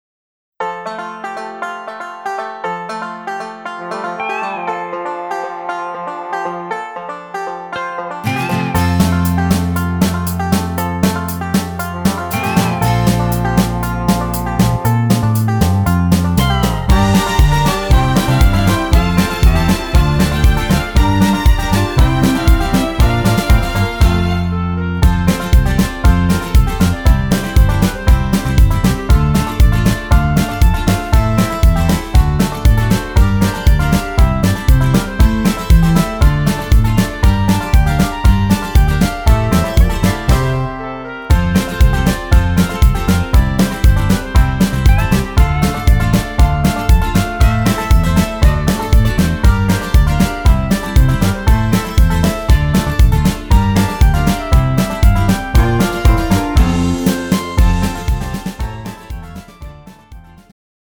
음정 여자키
장르 축가 구분 Pro MR